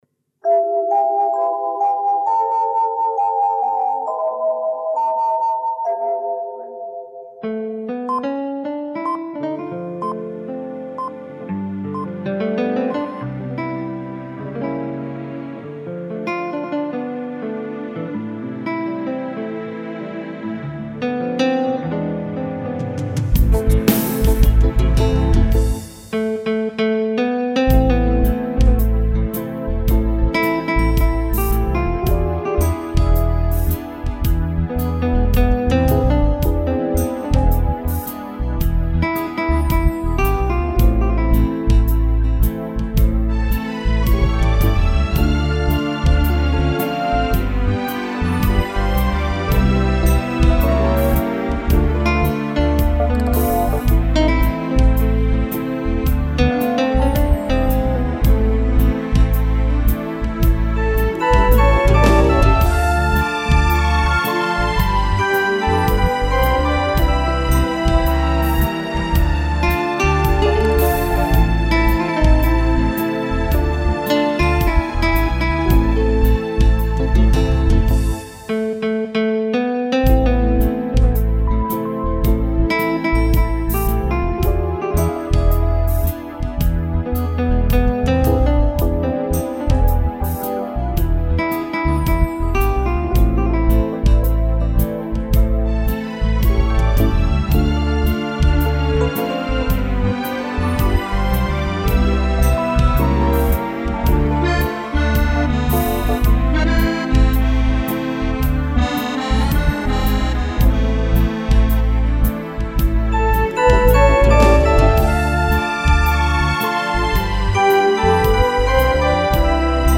Файл создан и записан студийными музыкантами.